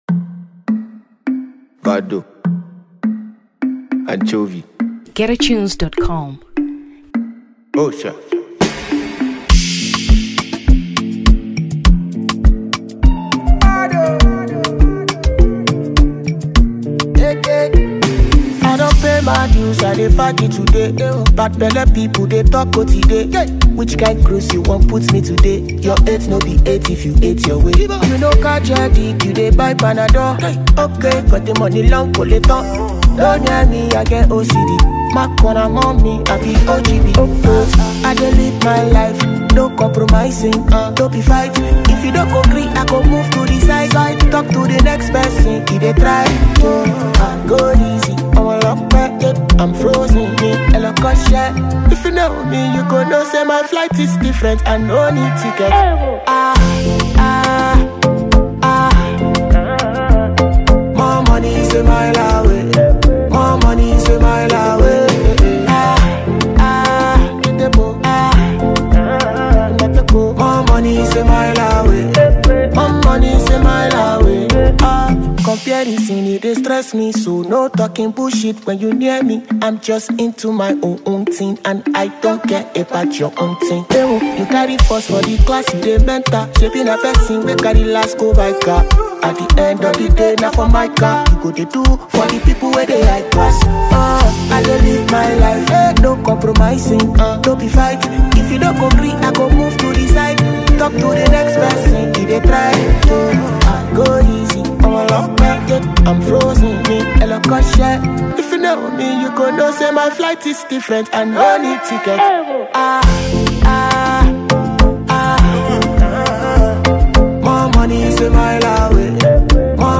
Afrobeats 2023 Nigeria